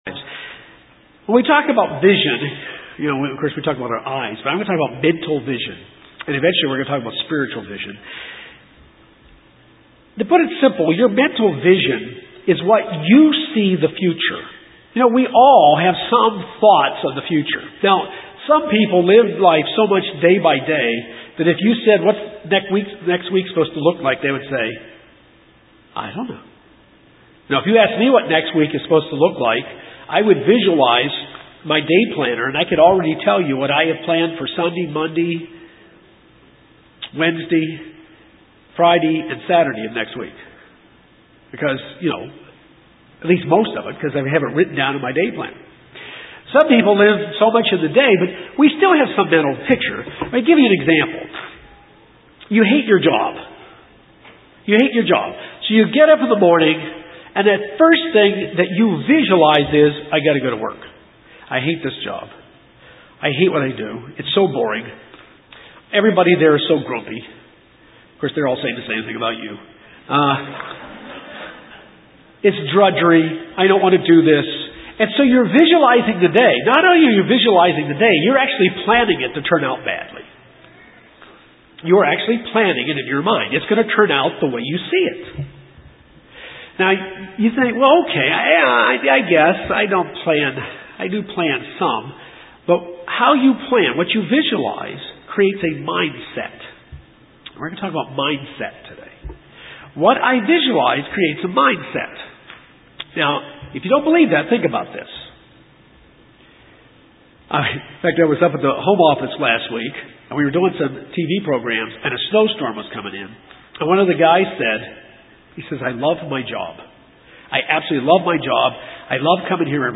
When our vision of the future God has planned for us does not go the way we thought it would it is usually because our vision is not lined up with the vision God has for us. This sermon will help us understand that we must stay in line with what God’s vision is for us.